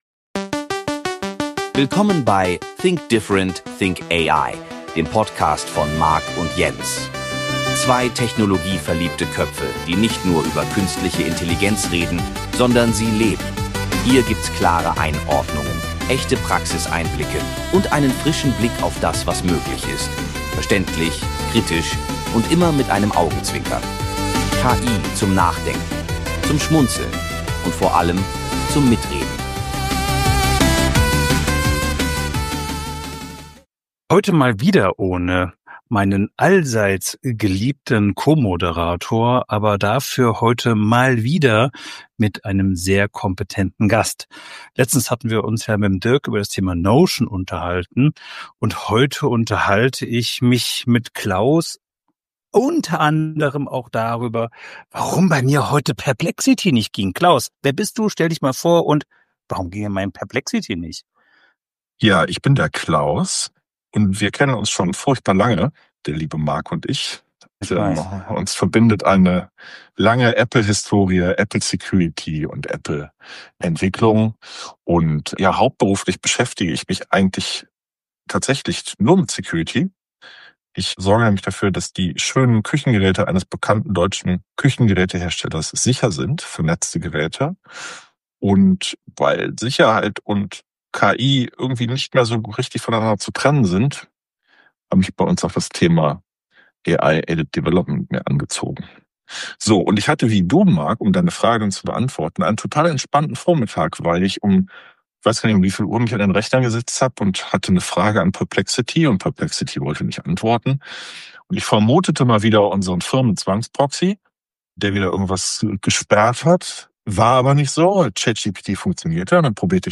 Ein technischer, ehrlicher und praxisnaher Deep Dive über Chancen, Risiken und den kommenden Wandel im Entwickleralltag.